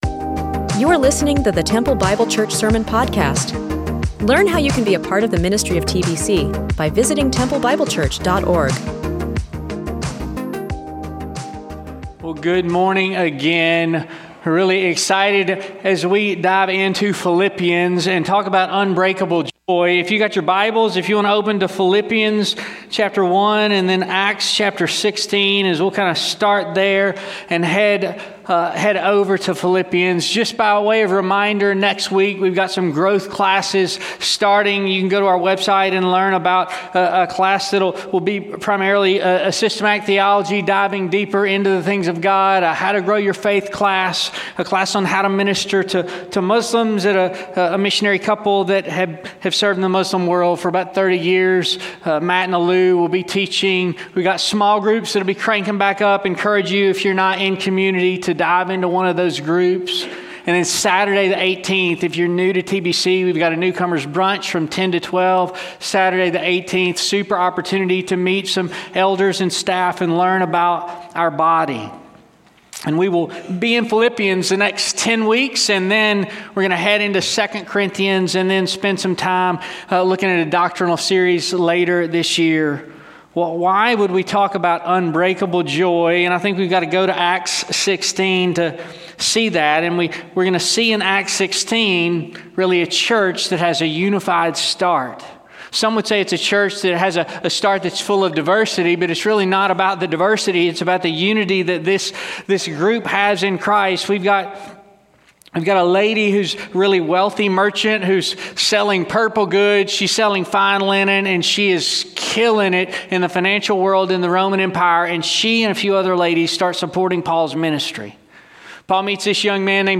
Weekly audio sermons from the pastors at Temple Bible Church in Temple, Texas.